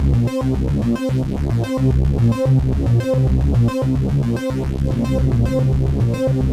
Index of /musicradar/dystopian-drone-samples/Droney Arps/110bpm
DD_DroneyArp2_110-C.wav